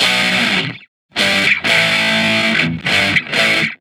Guitar Licks 130BPM (3).wav